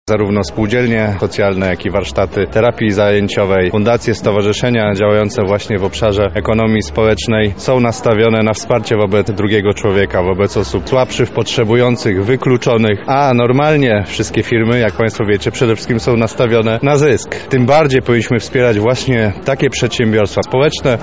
W naszym województwie istnieją 124 przedsiębiorstwa społeczne, natomiast podmiotów ekonomii społecznej zarezerwowanych w naszym regionie jest 8 tysięcy, w tym 6 tysięcy działających – tłumaczy Wicemarszałek Województwa Lubelskiego Marek Wojciechowski: